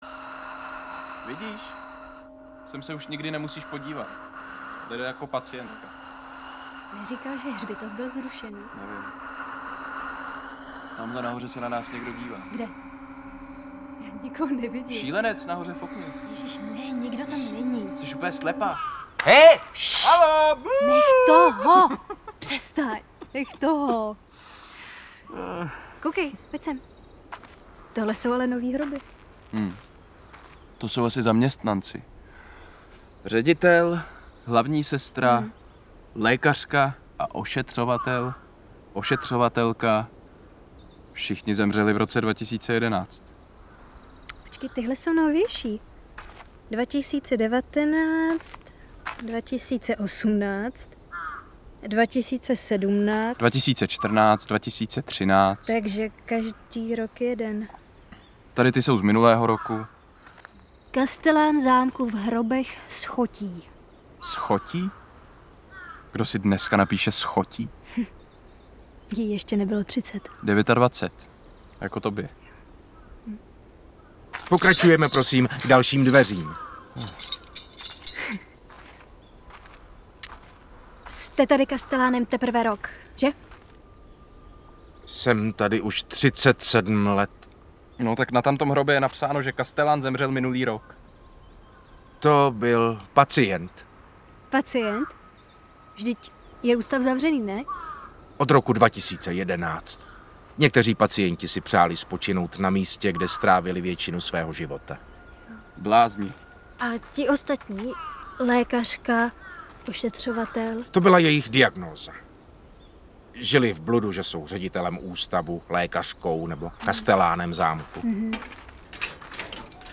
• Premiéru četby odvysílal Český rozhlas Dvojka v roce 2022.